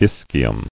[is·chi·um]